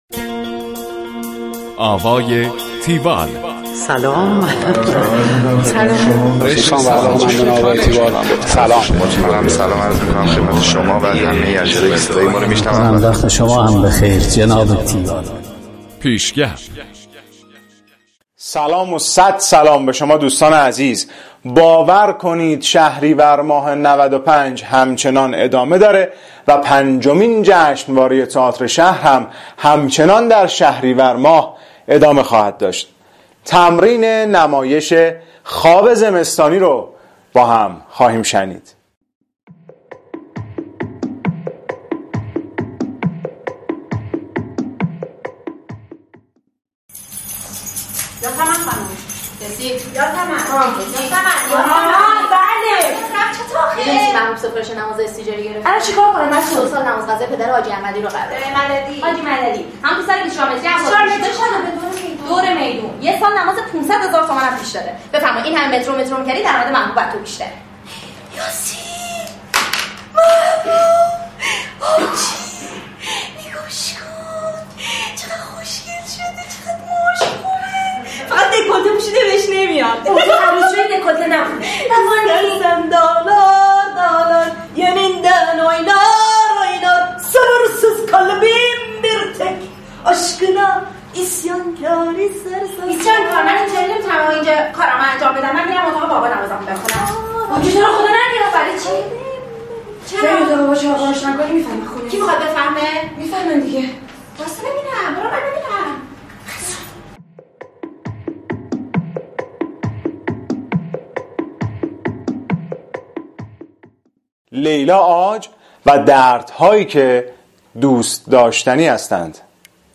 گزارش آوای تیوال از نمایش خواب زمستانی
گفتگو با